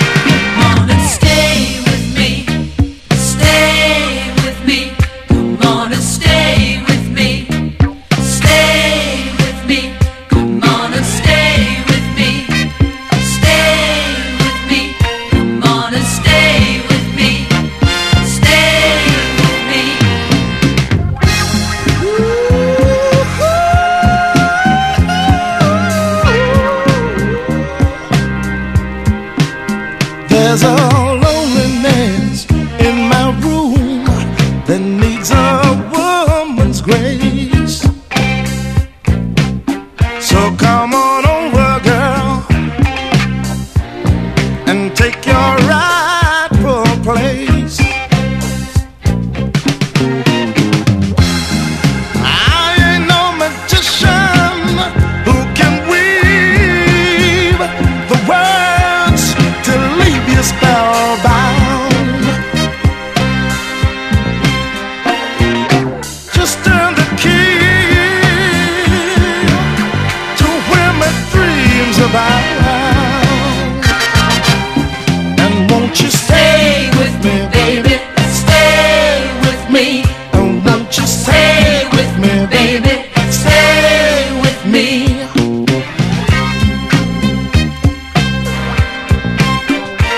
KIDS SOUL / CROSSOVER SOUL
疾走感あるノーザン・ビートにキュートなキッズ・ヴォーカルが乗る